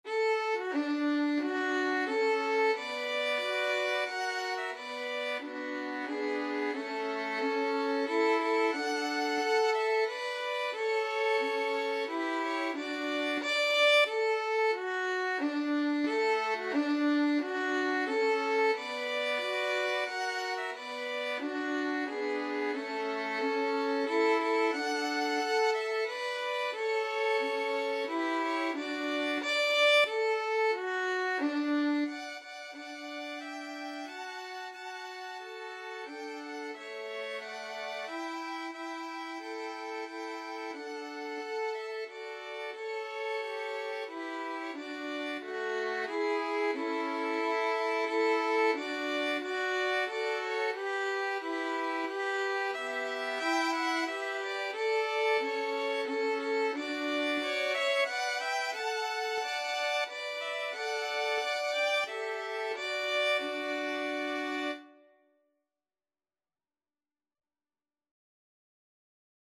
Free Sheet music for Violin Trio
D major (Sounding Pitch) (View more D major Music for Violin Trio )
Maestoso = c.90
Traditional (View more Traditional Violin Trio Music)